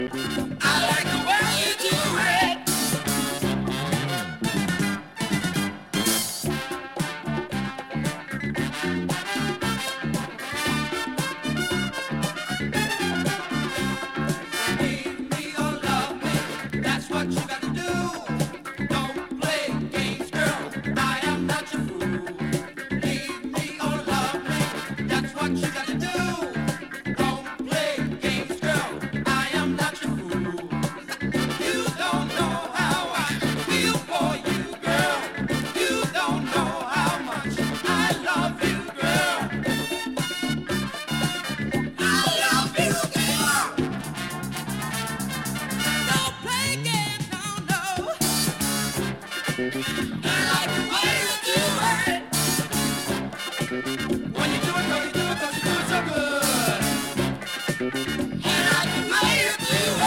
soul-dance group